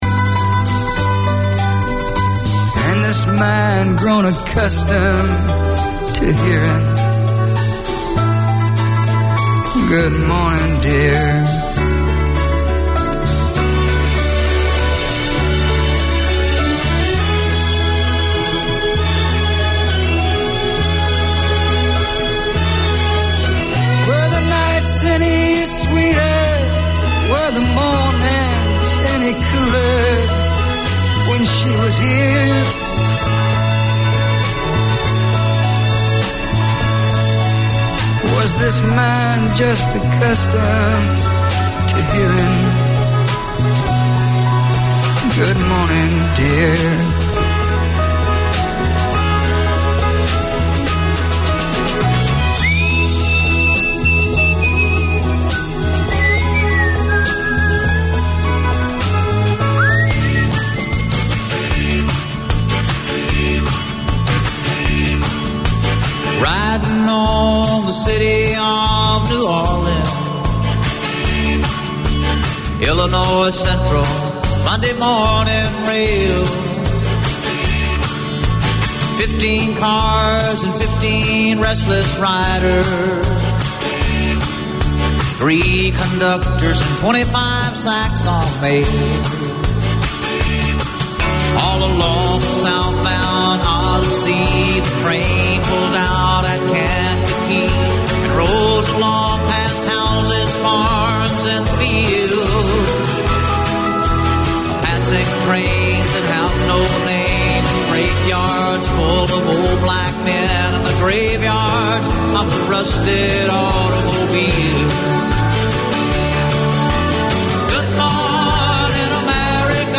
A programme for Sri Lankan Kiwis in Auckland and beyond, Sriwi Hada offers practical advice on everything from legal issues, immigration, getting around the city, and everything you need to know for surviving and thriving in Aotearoa. All this, and great music!